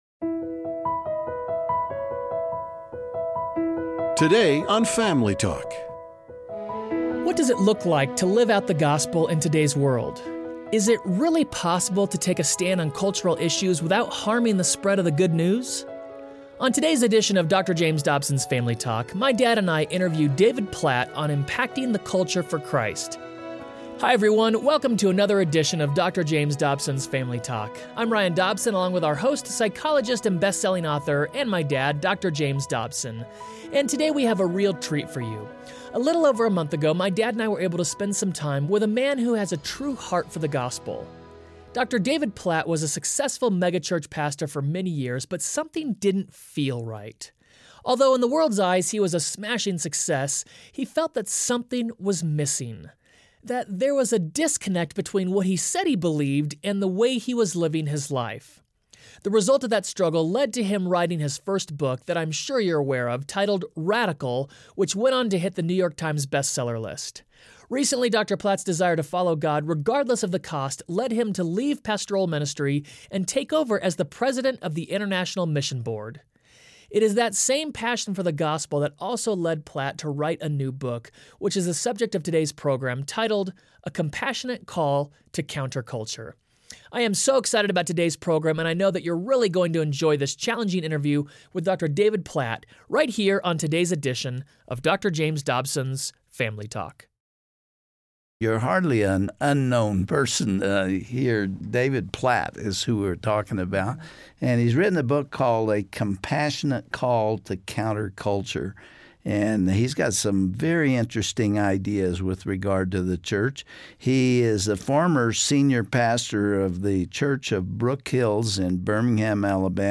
What does it look like to live out the Gospel in today's world? Dr. James Dobson interviews David Platt on impacting the culture for Christ.